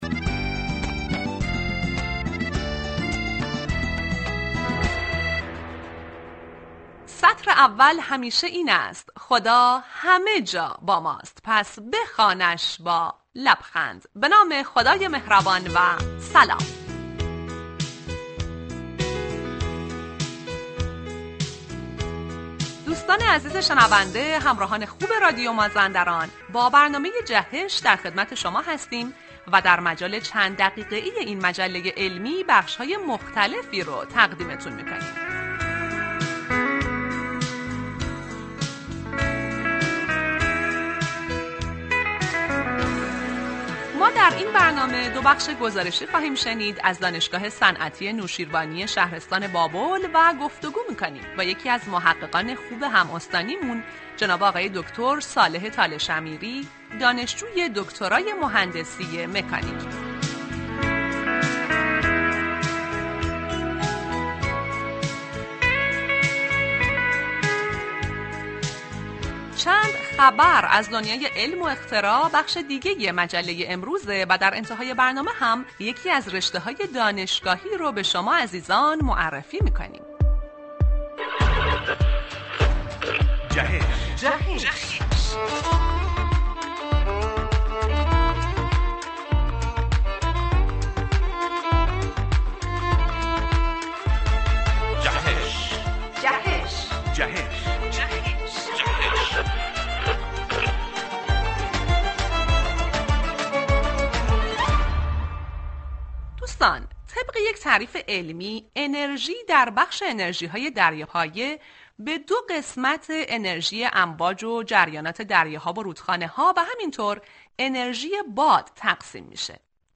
مصاحبه رادیویی